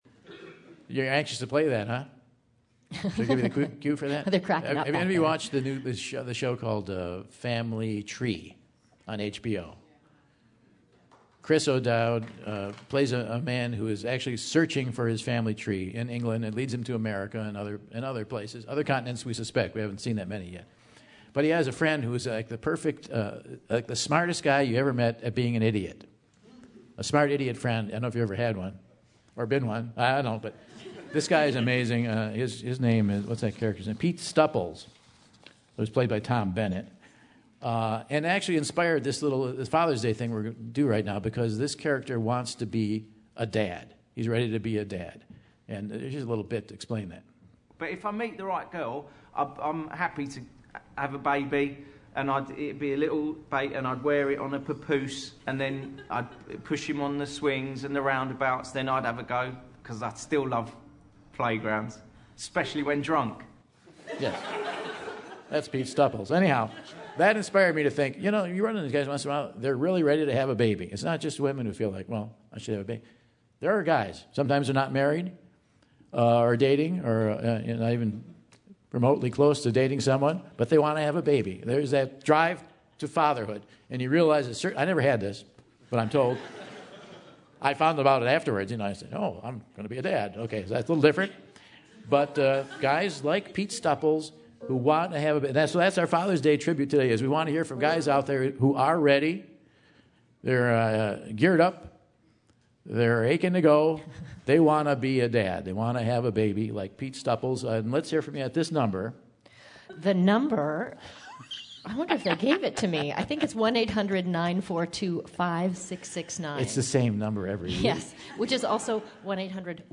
Guys ready to be dads, call-in and share their story.